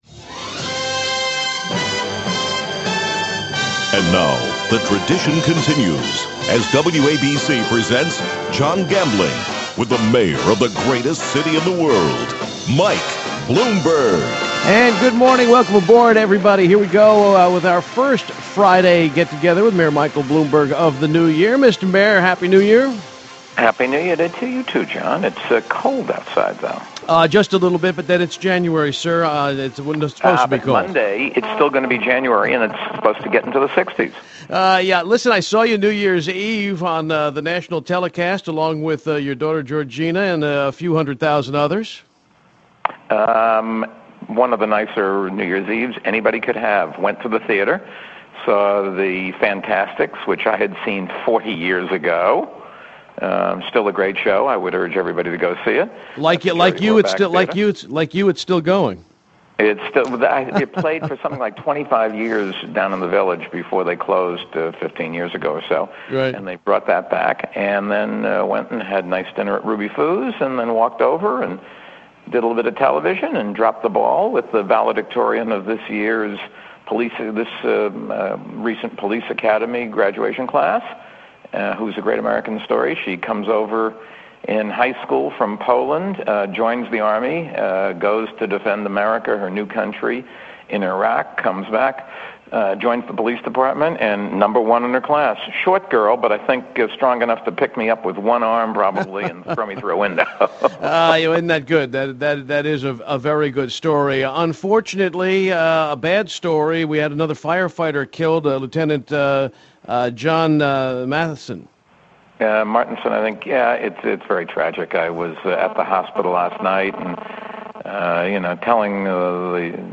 The Mayor discussed the results(he didn't mention candidates by name) during radio show on WABC (MP3 ).